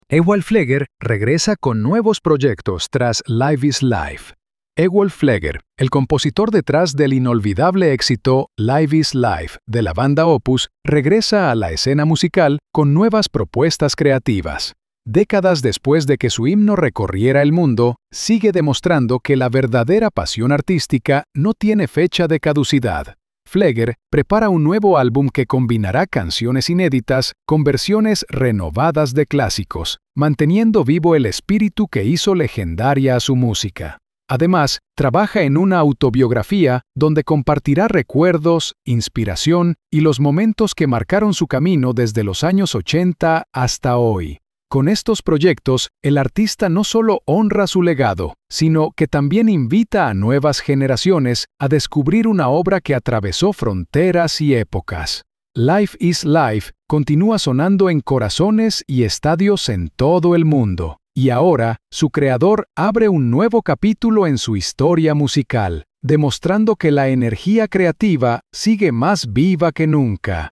Narración cultural · MP3 · ~45–60 segundos